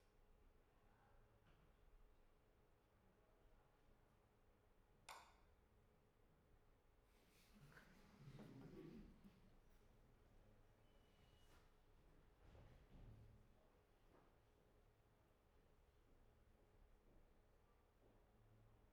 Background-noise.wav